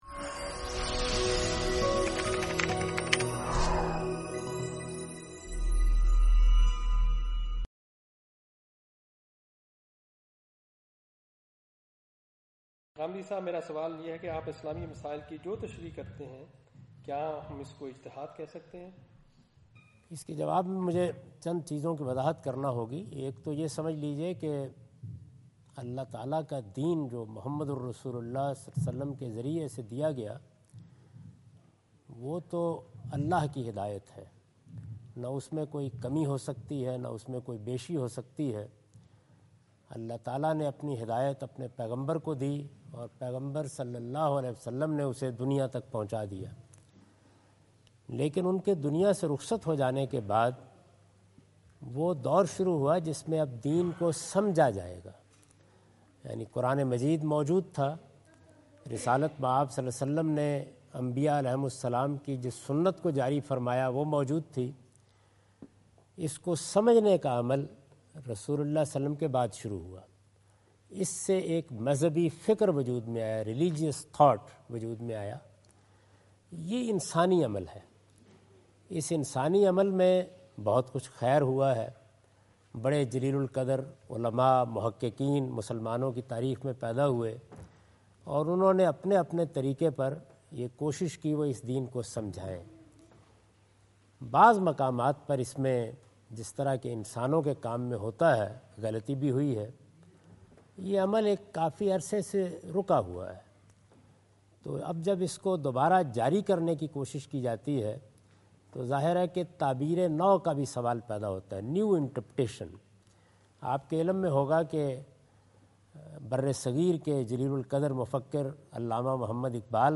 Javed Ahmad Ghamidi answer the question about "Can Ghamidi's Work Be Seen As Ijtihad?" during his visit in Canberra Australia on 03rd October 2015.
جاوید احمد غامدی اپنے دورہ آسٹریلیا کے دوران کینبرا میں "کیا غامدی صاحب کے علمی کام کو اجتہاد کا نام دیا جاسکتا ہے؟" سے متعلق ایک سوال کا جواب دے رہے ہیں۔